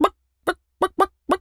chicken_cluck_bwak_seq_07.wav